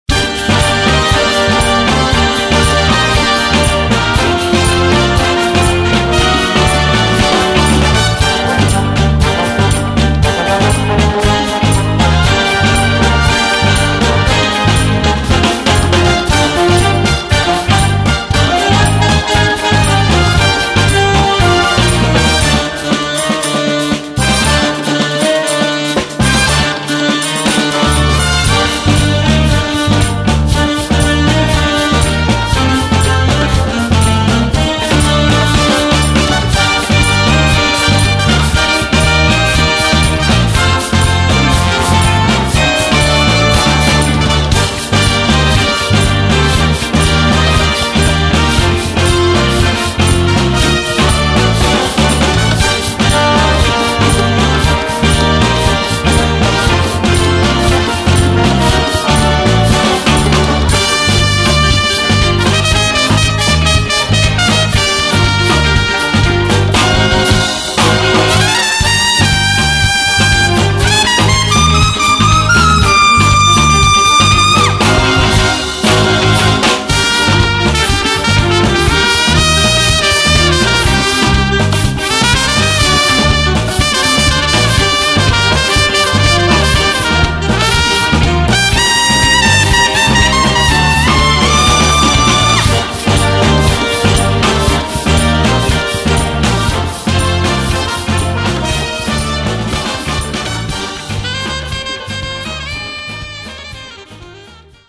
Voicing: Big Band